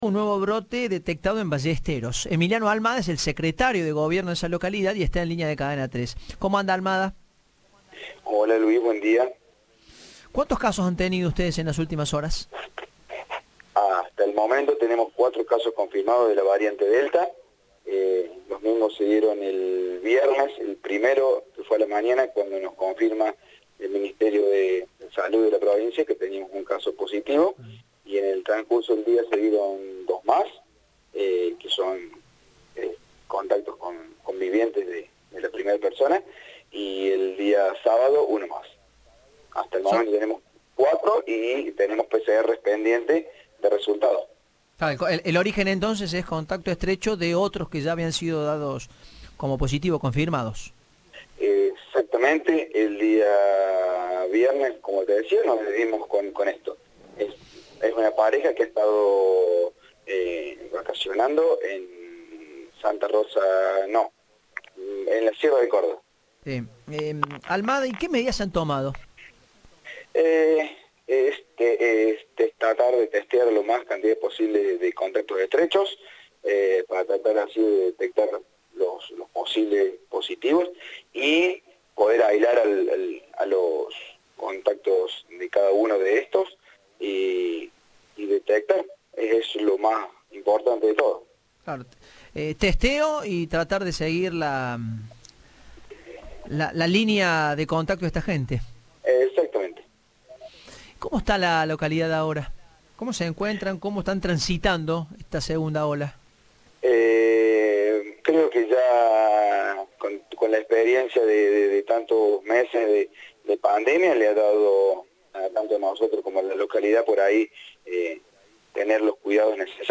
El secretario de Gobierno, Emiliano Almada, dijo a Cadena 3 que tratan de testear a la mayor cantidad de contactos estrechos para aislarlos.
Entrevista